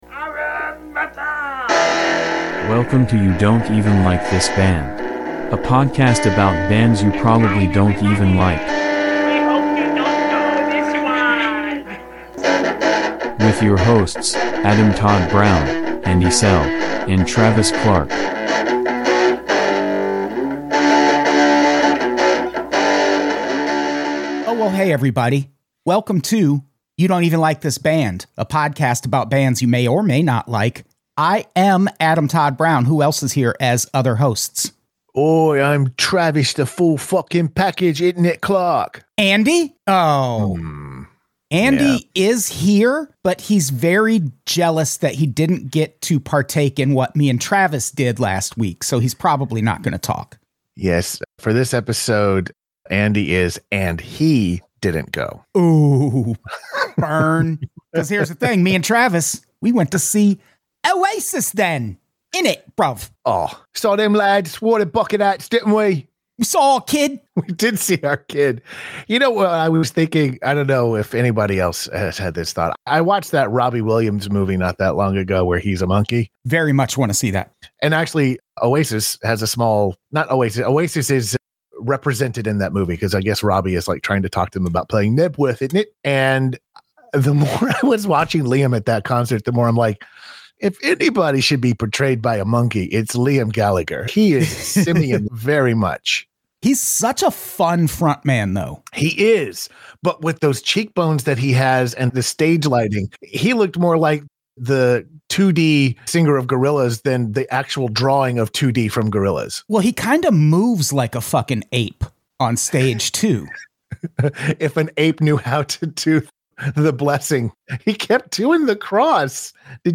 A podcast series about the history of some of the most controversial and polarizing bands and musicians of all-time. Hosted by comedians